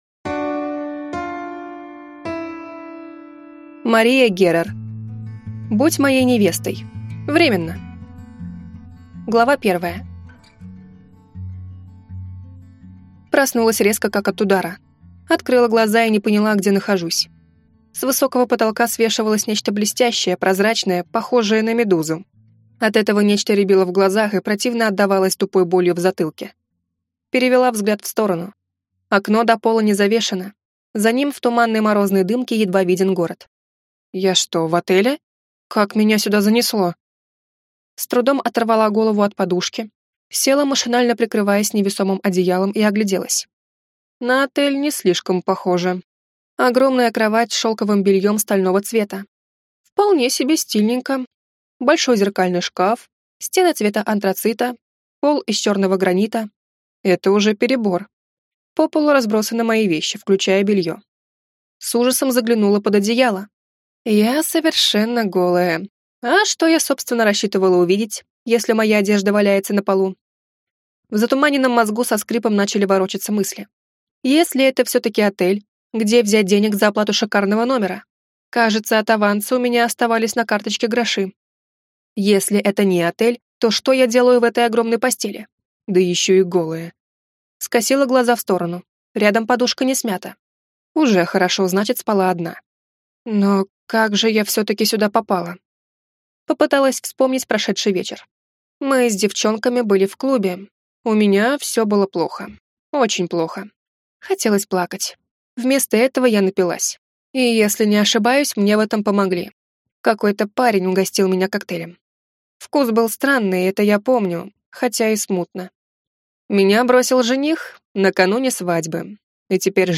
Аудиокнига Будь моей невестой. Временно | Библиотека аудиокниг